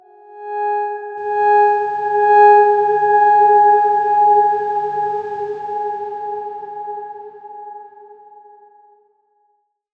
X_Darkswarm-G#4-mf.wav